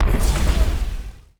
weapon_flame_004.wav